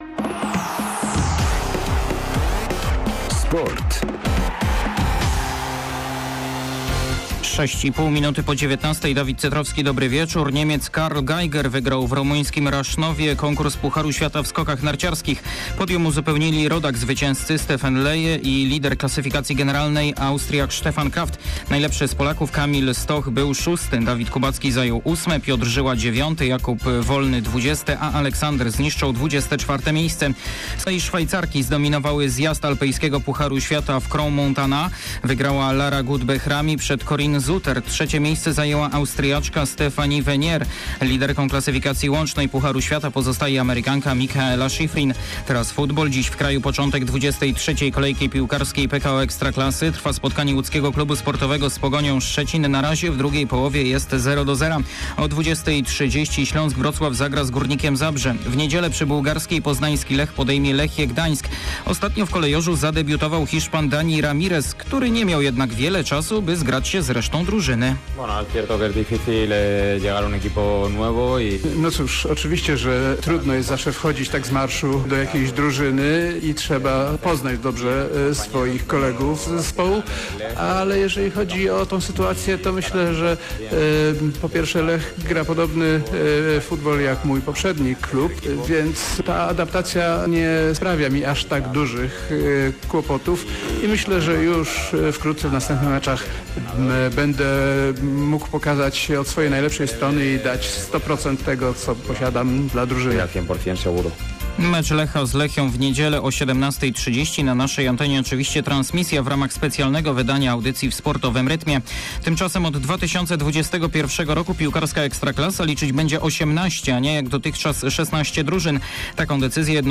21.02. SERWIS SPORTOWY GODZ. 19:05